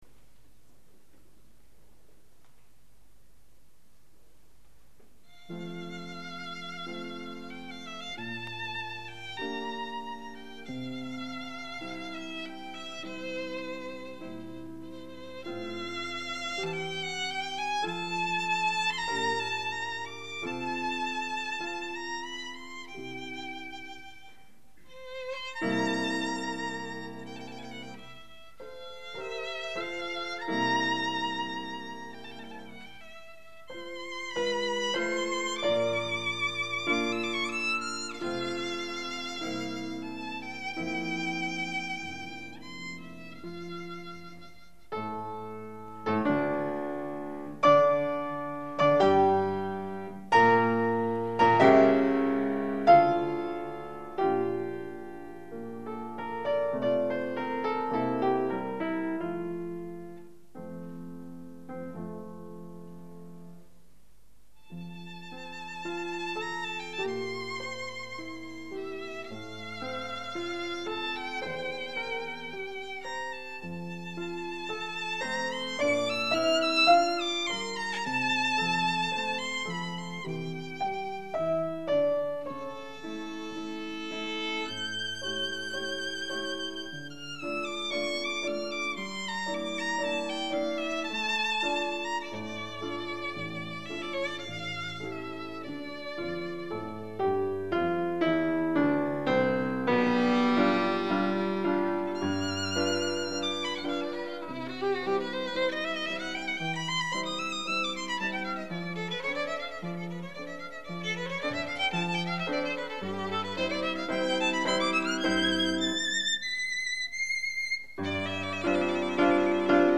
violin
piano
Bösendorfer Mödlinger Bühne, Mödling, 14 November 1985.
Romance for violin and piano in F major